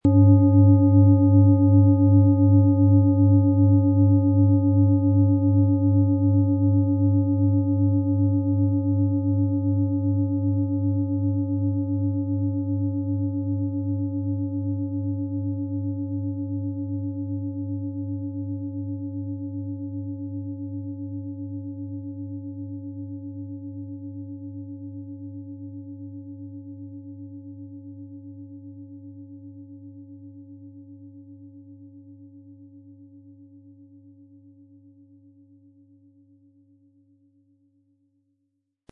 PlanetentonPluto & Biorhythmus Körper (Höchster Ton)
MaterialBronze